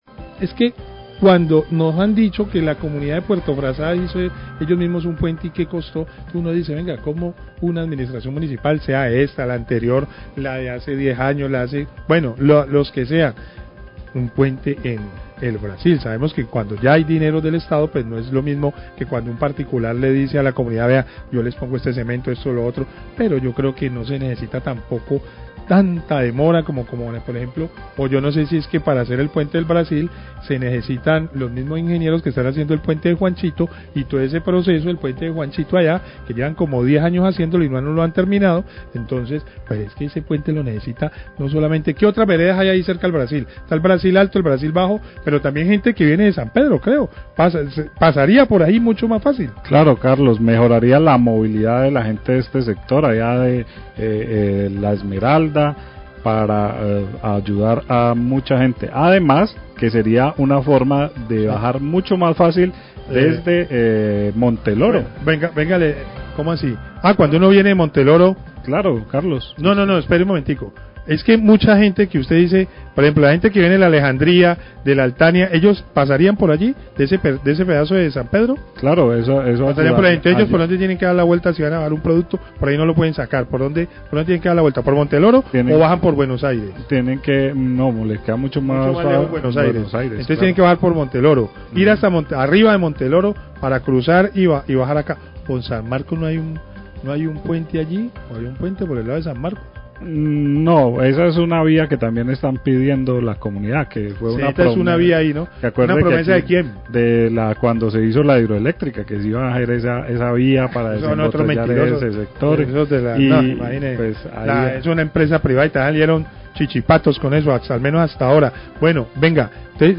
Periodistas mencionan via no entregada cuando se construyó hidroléctrica en zona rural Tuluá
Radio